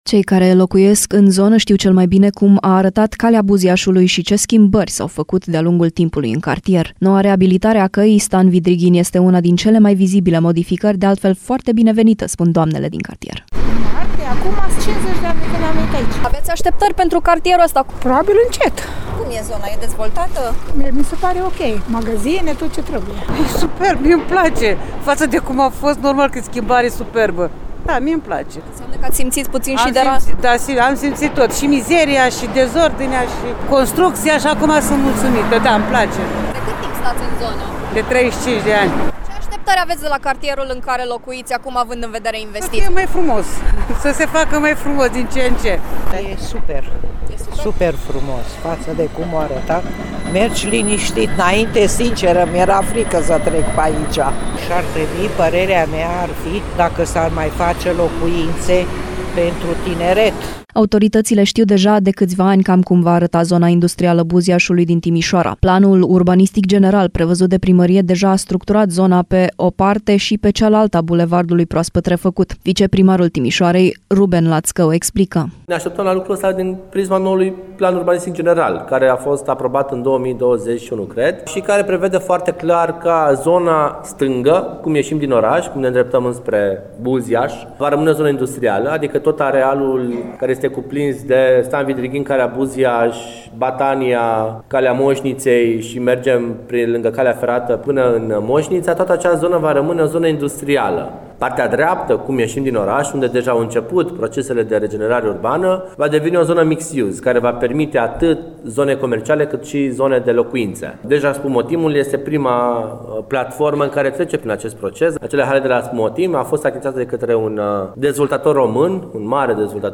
Mi se pare ok. Magazine, tot ce trebuie”, spune o tânără.
E superb, mie-mi place. Față cum a fost normal că e o schimbare superbă”, spune o timișoreancă.